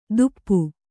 ♪ duppa